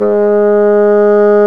Index of /90_sSampleCDs/Roland L-CDX-03 Disk 1/WND_Bassoons/WND_Bassoon 2
WND BASSOO0C.wav